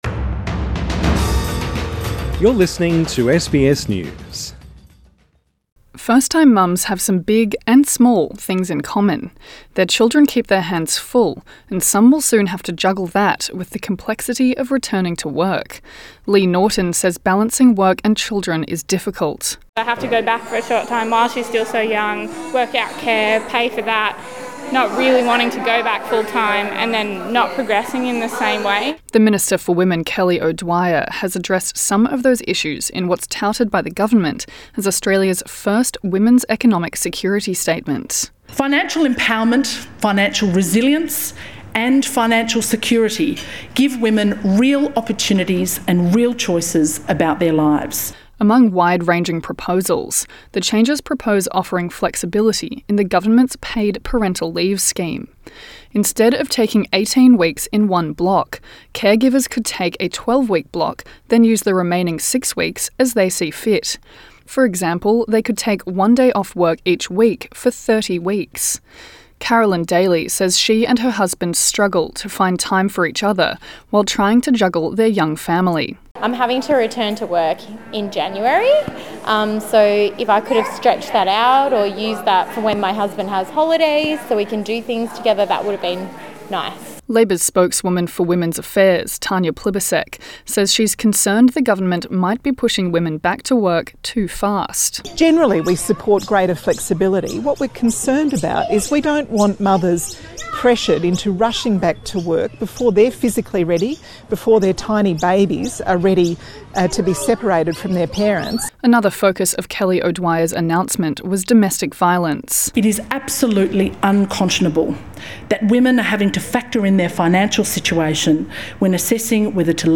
Minister for Women Kelly O'Dwyer detailed the initiatives while delivering Australia's first women's economic security statement at the National Press Club in Canberra.